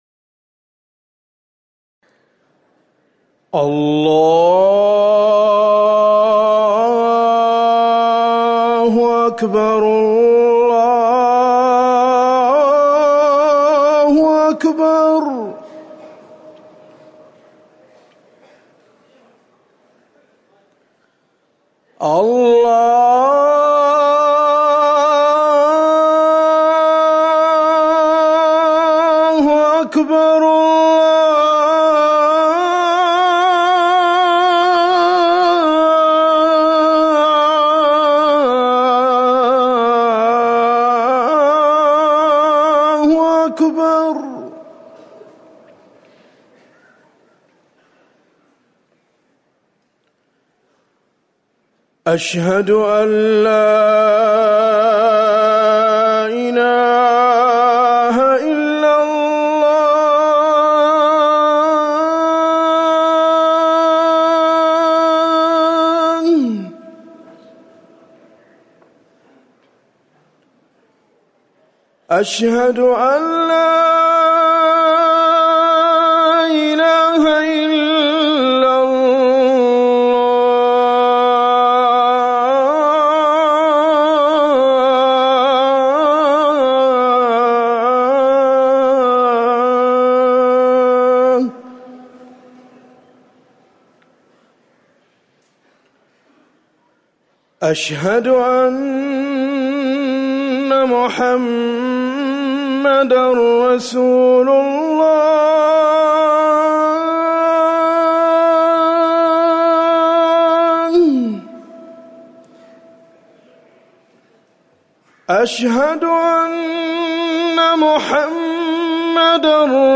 أذان الفجر الثاني - الموقع الرسمي لرئاسة الشؤون الدينية بالمسجد النبوي والمسجد الحرام
تاريخ النشر ١١ محرم ١٤٤١ هـ المكان: المسجد النبوي الشيخ